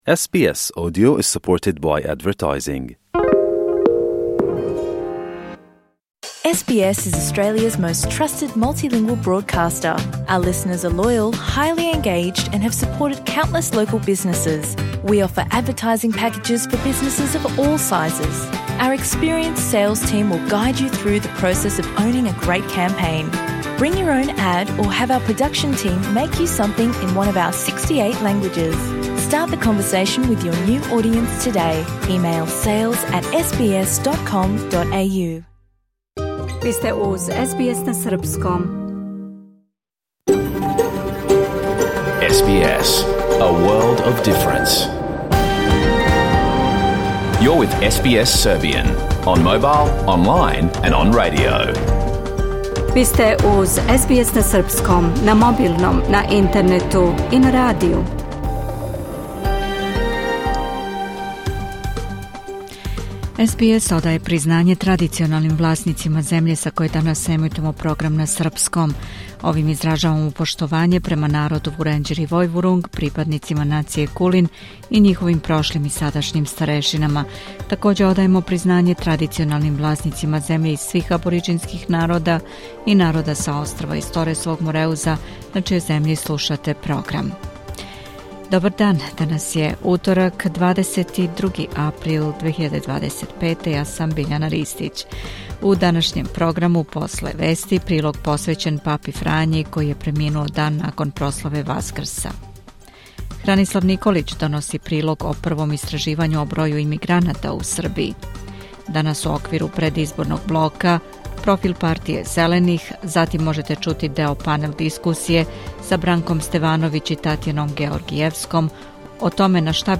Програм емитован уживо 22. априла 2025. године
Уколико сте пропустили данашњу емисију, можете је послушати у целини као подкаст, без реклама.